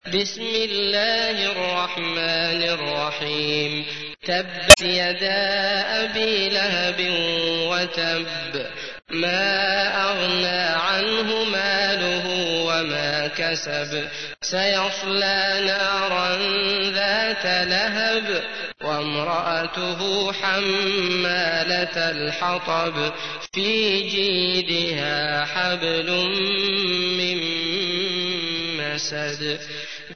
تحميل : 111. سورة المسد / القارئ عبد الله المطرود / القرآن الكريم / موقع يا حسين